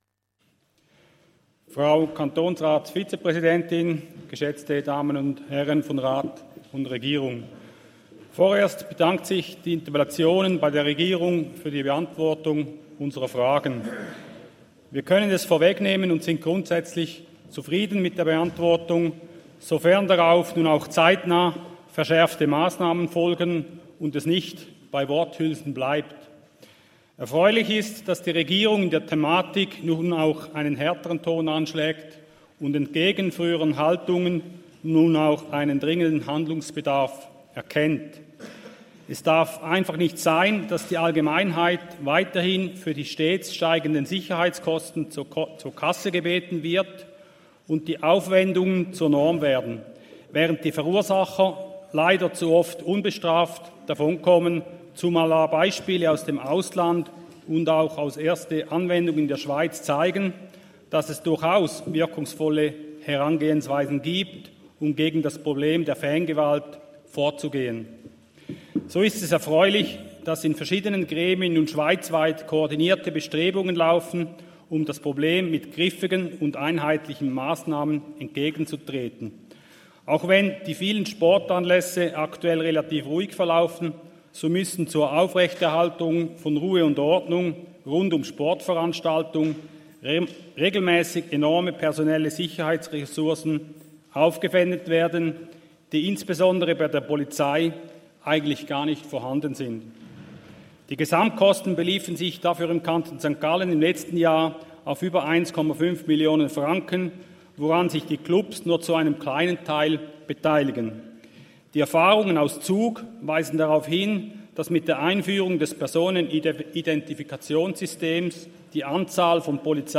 19.9.2023Wortmeldung
Session des Kantonsrates vom 18. bis 20. September 2023, Herbstsession